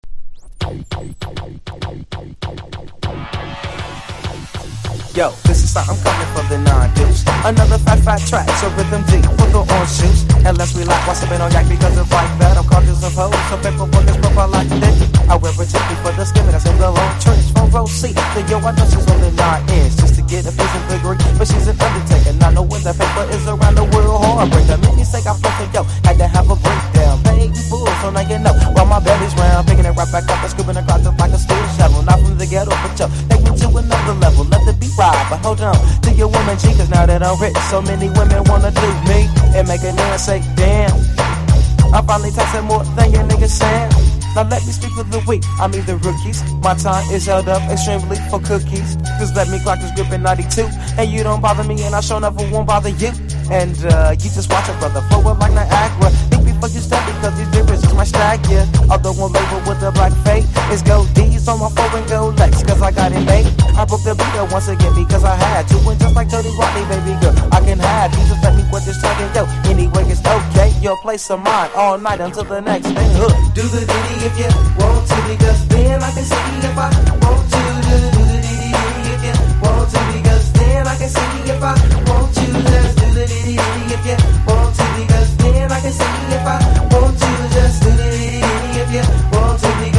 MIX CD等でお馴染みのメロウ・ウェッサイ・クラシック！！